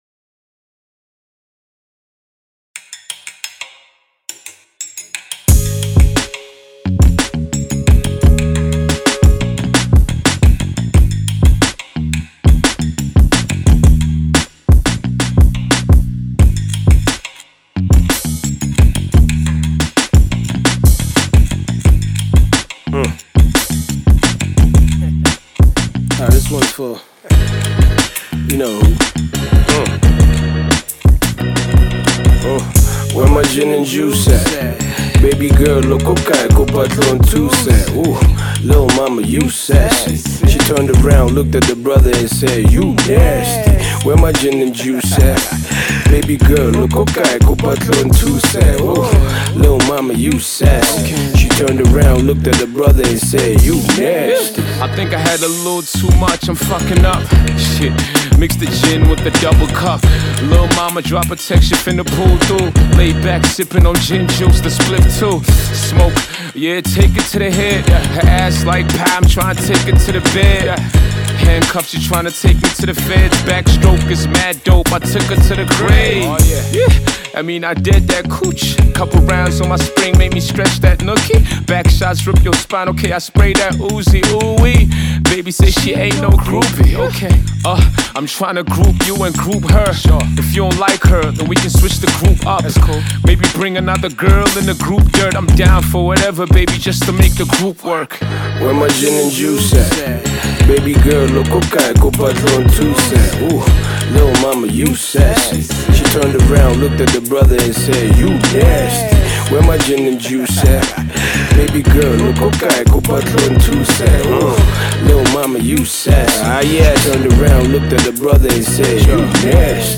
South African rapper
guitar